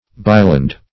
biland - definition of biland - synonyms, pronunciation, spelling from Free Dictionary Search Result for " biland" : The Collaborative International Dictionary of English v.0.48: Biland \Bi"land\, n. A byland.